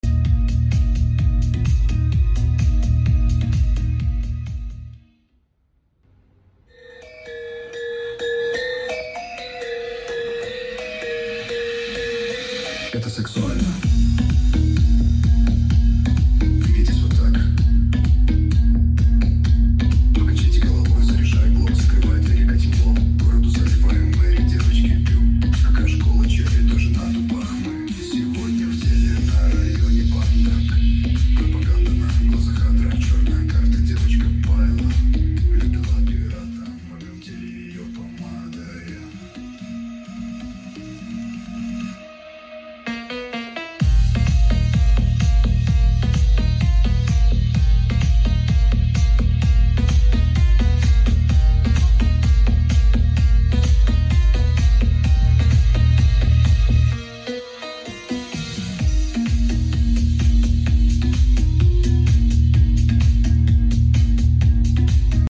Speaker Connection & Sound Test
Real Bass Check